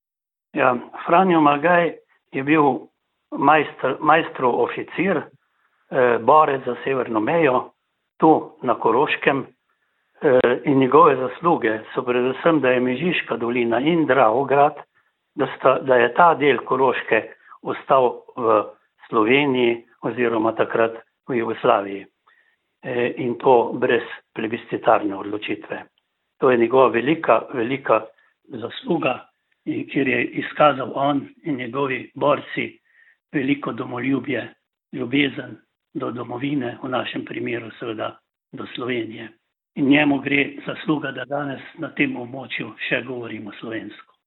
Ob 106. obletnici smrti Franja Malgaja je včeraj popoldne v razstavišču na gradu Ravne potekala spominska slovesnost.